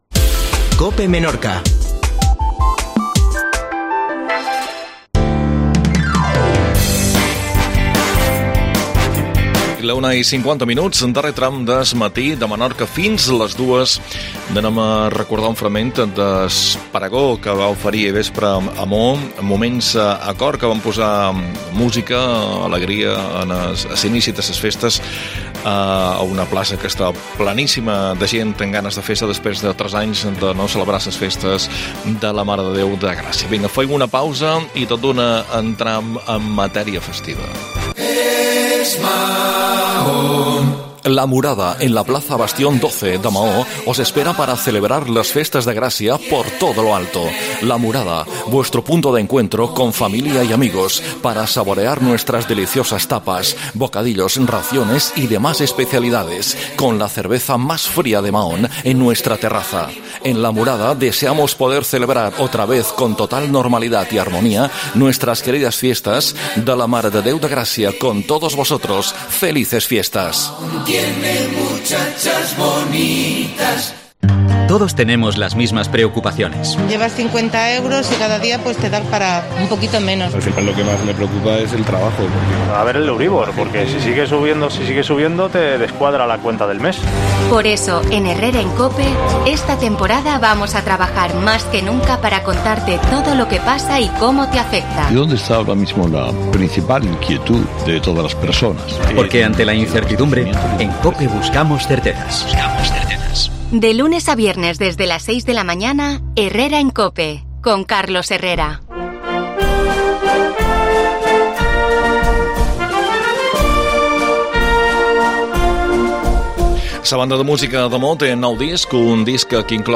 AUDIO: Prego festes de Gracia 2022 amb Moments a Cor